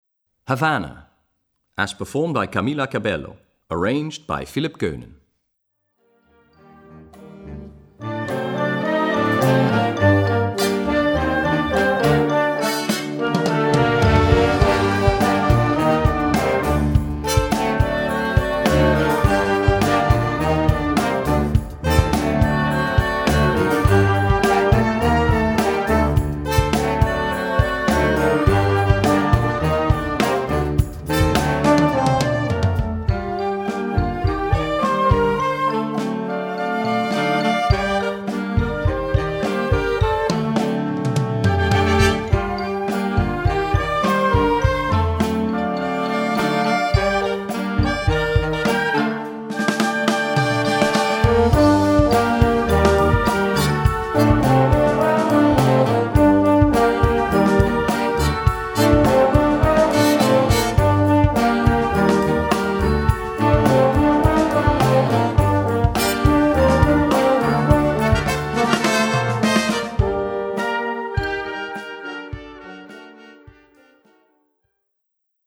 Gattung: Moderner Einzeltitel
Besetzung: Blasorchester